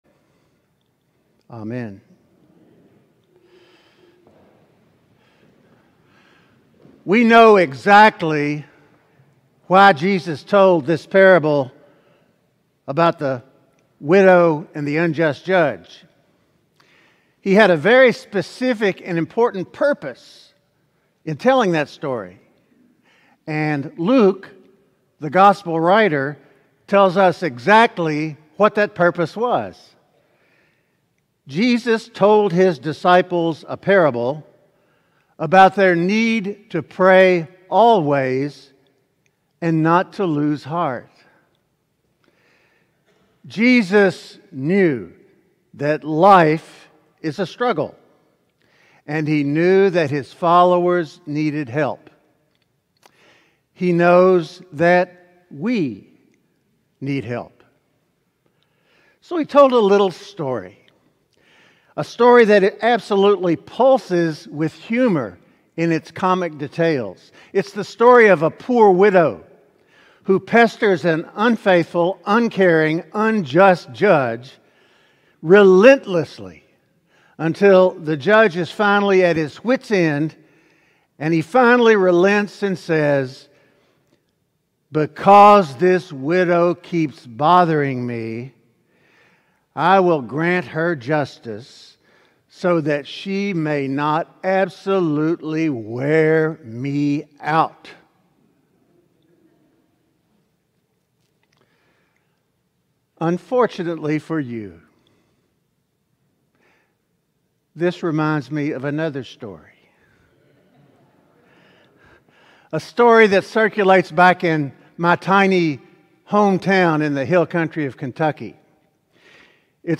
Sermon: Struggling with God